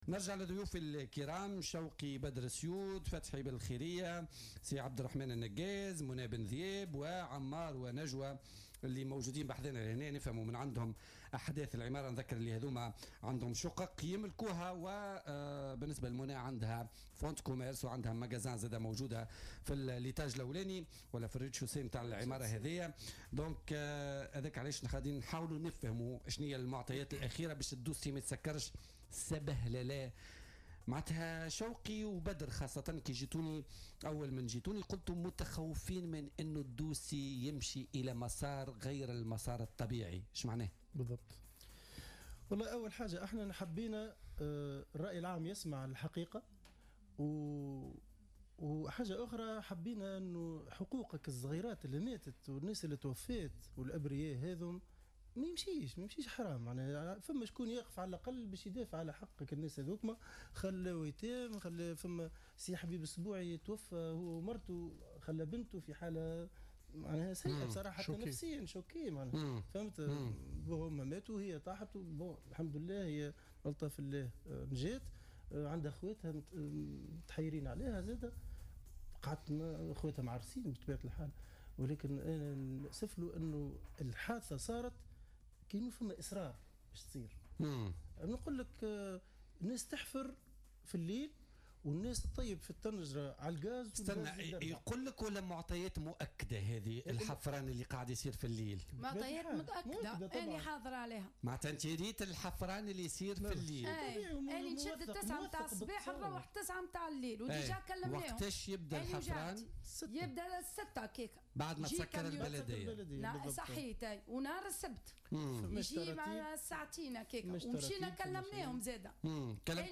استضاف برنامج "بوليتيكا" اليوم الجمعة 13 أكتوبر 2017، مجموعة من مالكي شقق ومحلات تجارية في عمارة "تروكاديرو" بشارع الجمهورية بسوسة التي انهارت يوم 05 أكتوبر 2017 وأسفرت عن وفاة 6 أشخاص من متساكني العمارة.